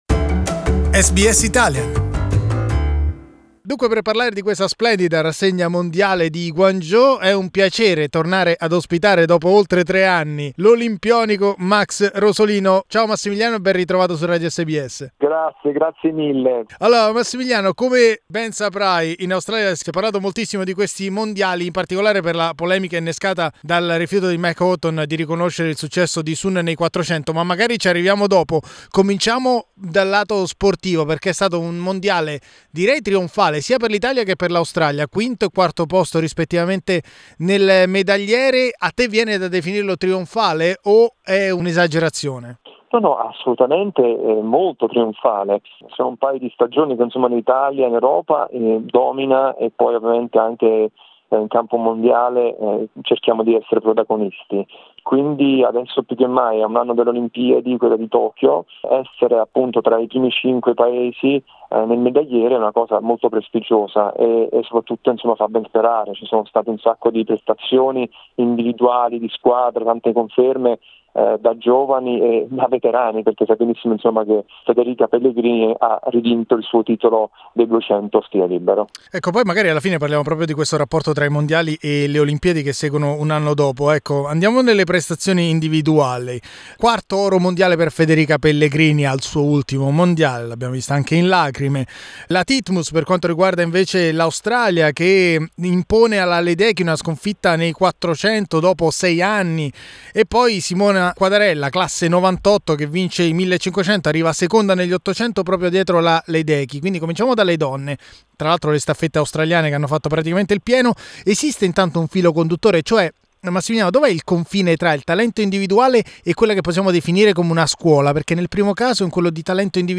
Yet, interviewed by SBS Italian, Rosolino criticized Mack Horton decision of protesting against Sun Yang, winner at the 400 meter freestyle event.